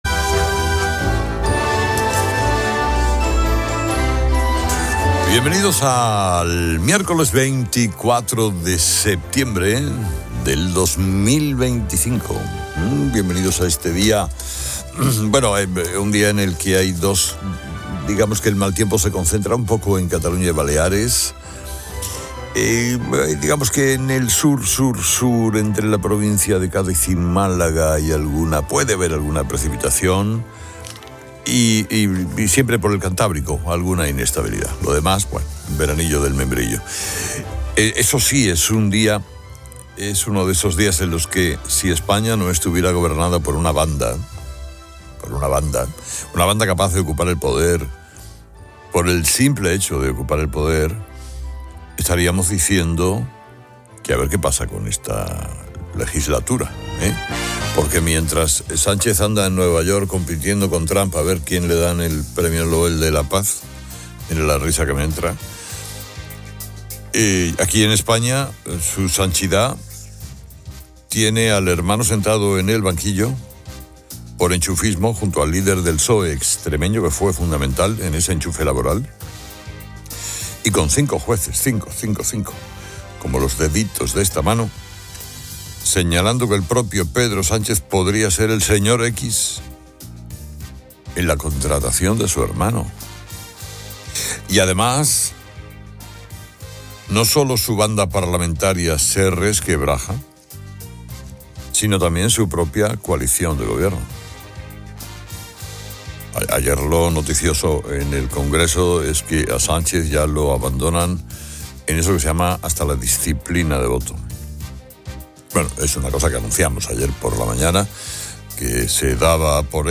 Carlos Herrera, de COPE, presenta las noticias más destacadas de la mañana del miércoles 24 de septiembre de 2025.